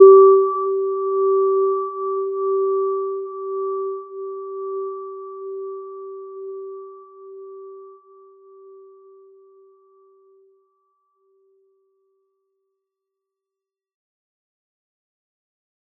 Gentle-Metallic-1-G4-p.wav